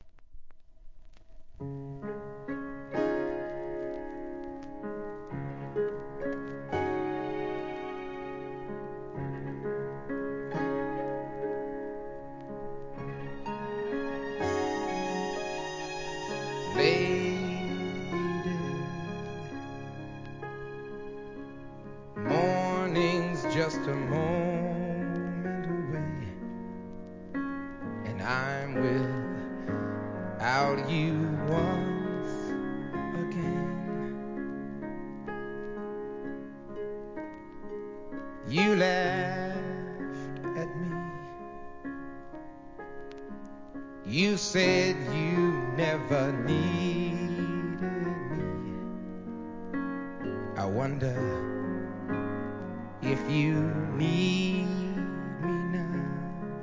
¥ 660 税込 関連カテゴリ SOUL/FUNK/etc...